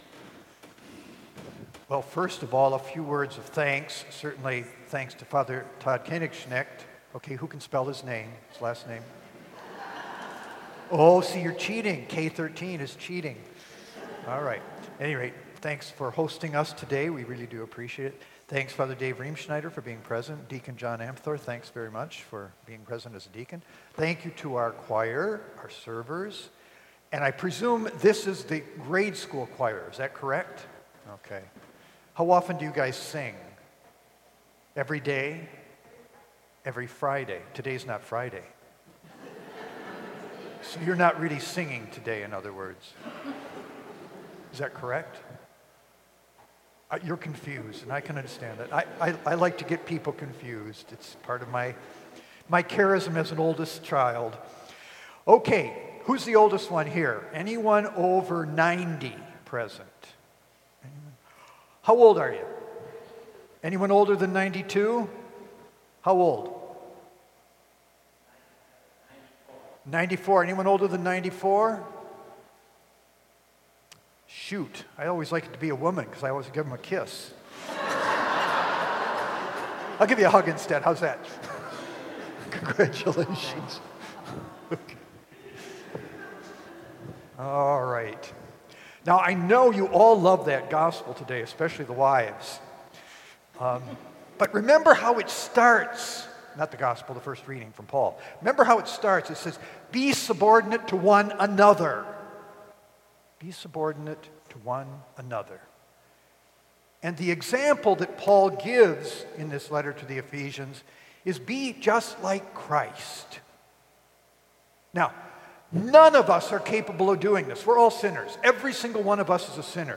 Senior Mass with Bishop Boyea on 10/25/2022
Click below to hear the Bishop's Homily and to see some pictures from the Mass.